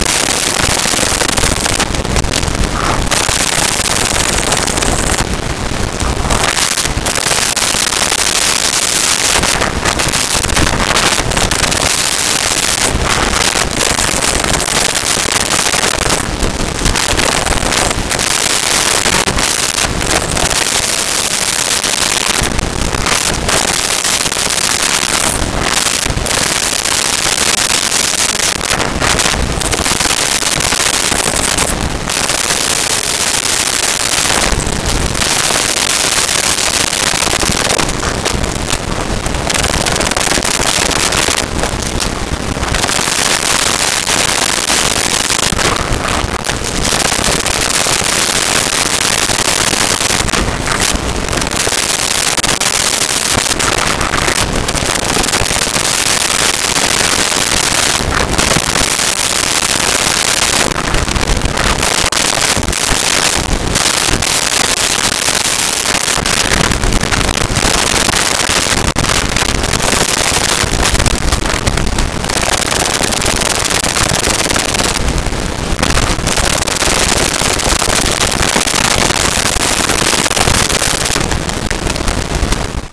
Dog Small Growling Licking
Dog Small Growling Licking.wav